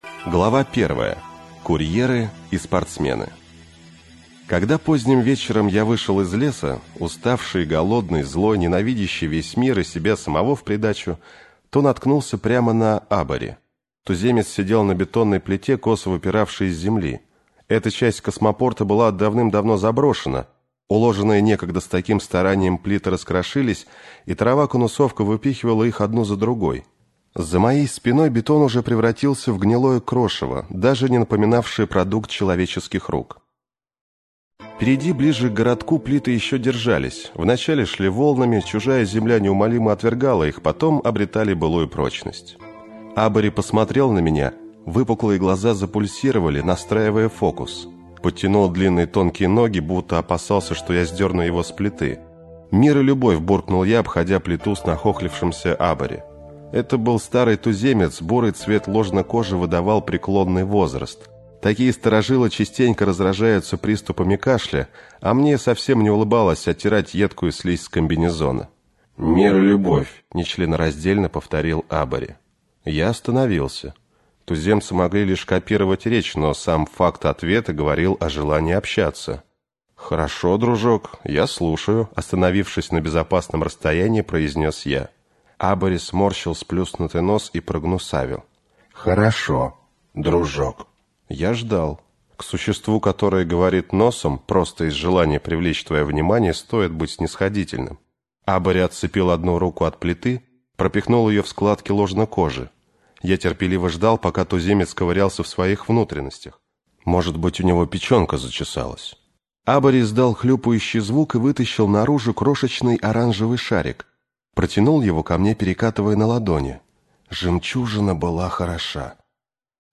Аудиокнига Тени снов - купить, скачать и слушать онлайн | КнигоПоиск